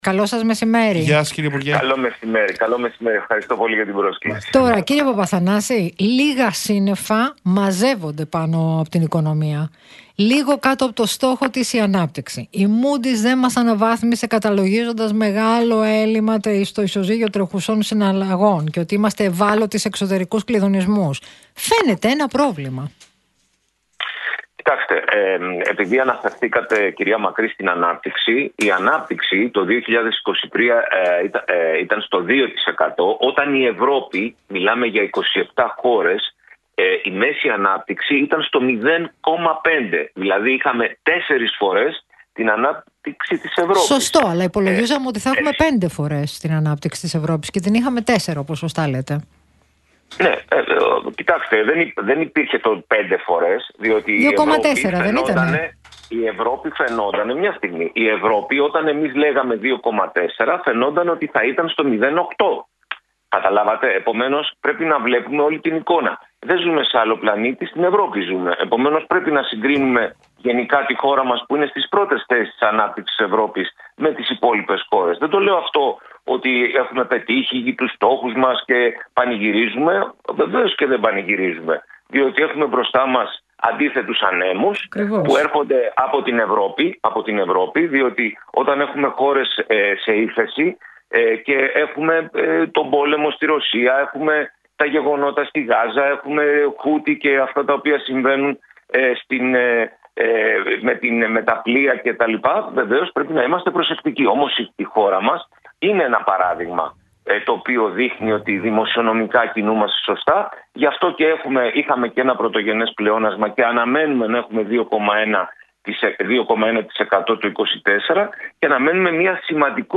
Πετύχαμε όλους τους στόχους των απορροφήσεων, οι κοινοτικοί πόροι πηγαίνουν στην οικονομία. Συνέντευξη στον Realfm.
Ακούστε το ηχητικό απόσπασμα από την συνέντευξη του κ. Παπαθανάση εδώ.